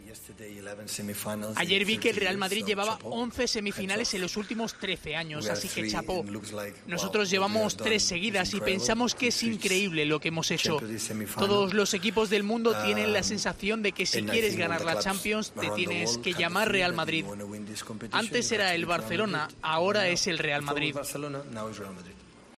El entrenador habló de la semifinal que le enfrentará al Real Madrid tras la eliminación del año pasado y después de eliminar al Bayern de Munich en cuartos de final.